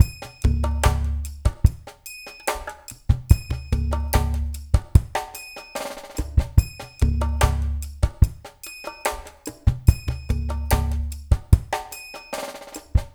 BOL FULLDR-R.wav